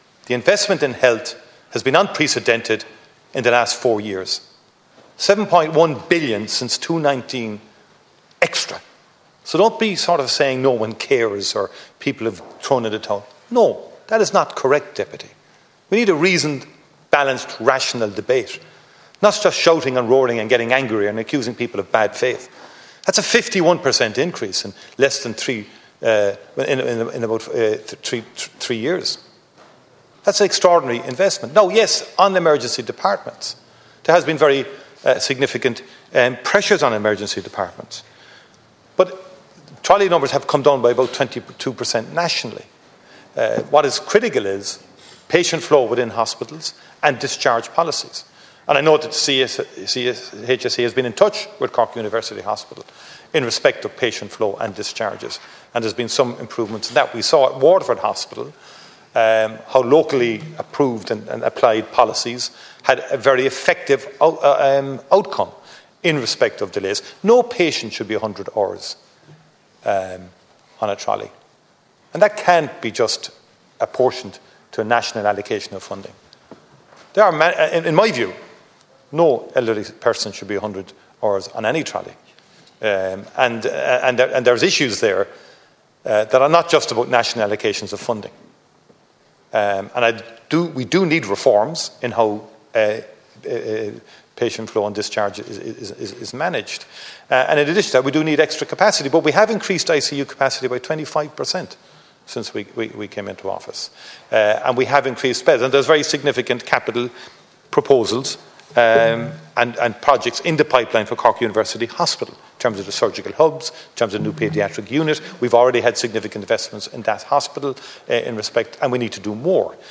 There were clashes in the Dail this afternoon between Sinn Fein and Tanaiste Michael Martin over the government’s approach to the overcrowding crisis.
Donegal Deputy Pearse Doherty told the Tanaiste that Cork University Hospital is at 300% capacity, a situation which is untenable, prompting angry exchanges between the Ceann Comhairle and Cork TD Cathal Gould, when the Sinn Fein Deputy attempted to intervene.